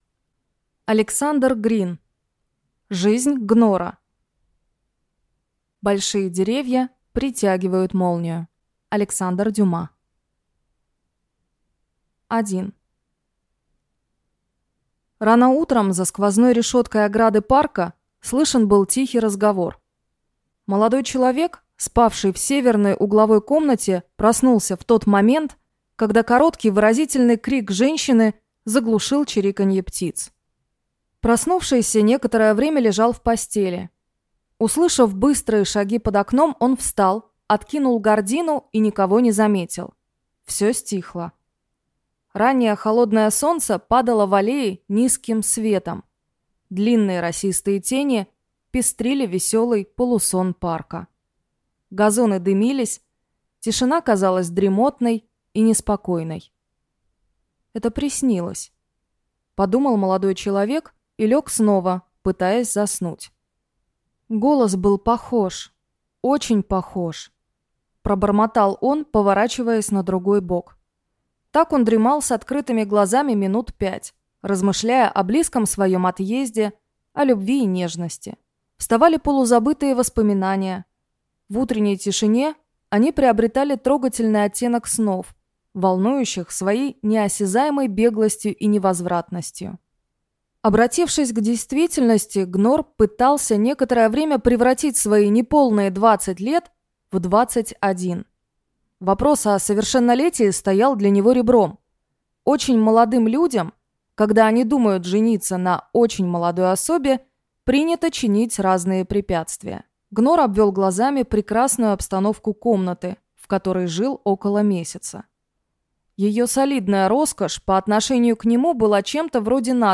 Аудиокнига Жизнь Гнора | Библиотека аудиокниг